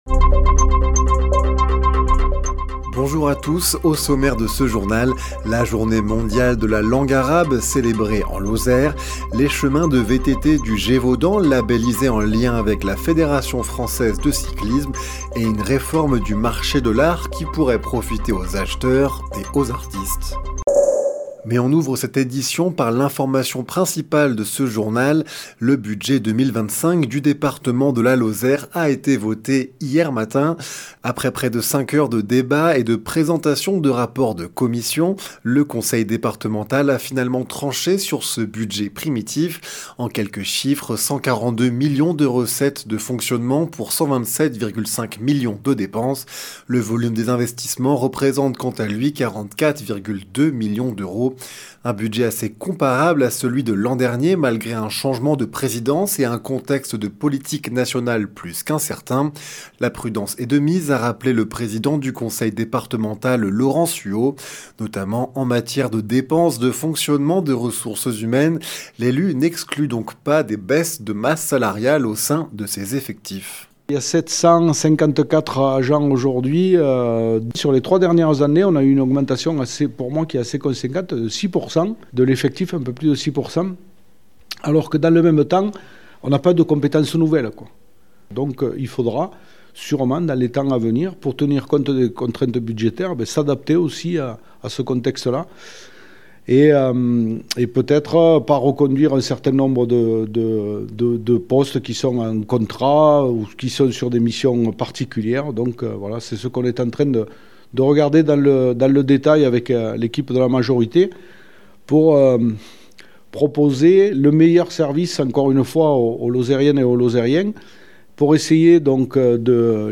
Les informations locales
Le journal sur 48FM